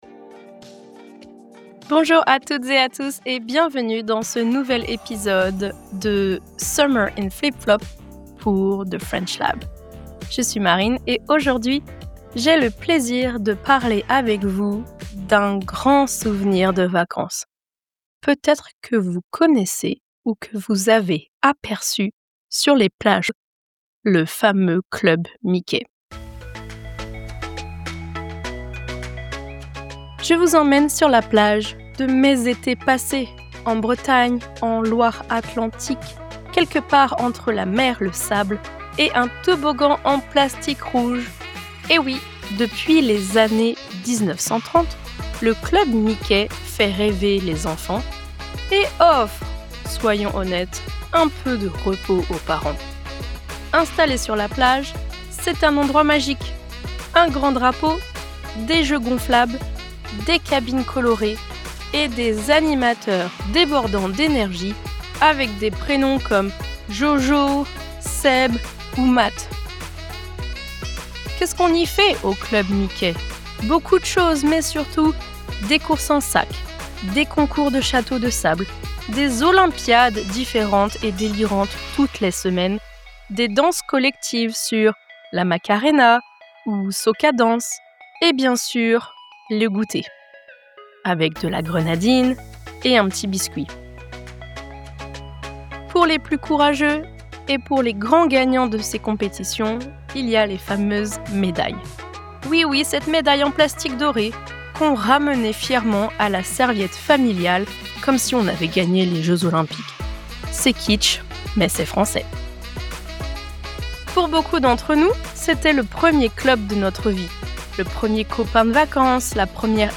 Slow-paced, clear pronunciation, and real-life vocabulary—perfect for learning on the go!